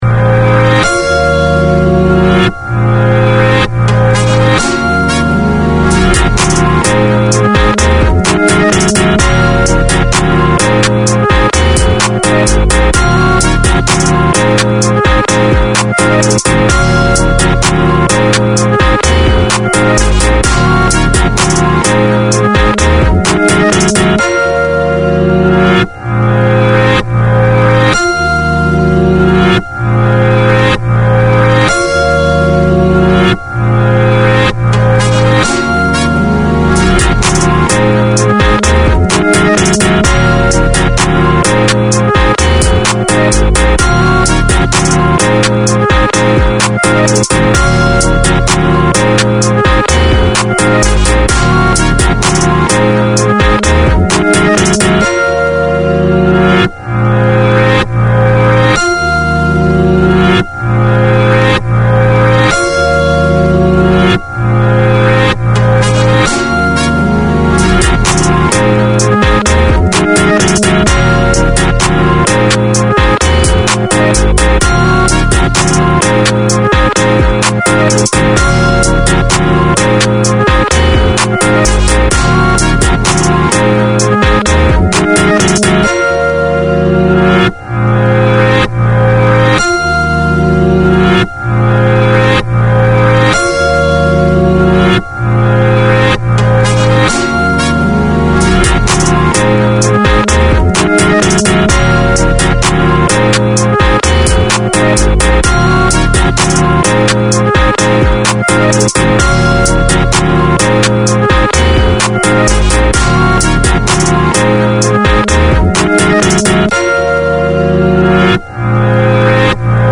Radio made by over 100 Aucklanders addressing the diverse cultures and interests in 35 languages.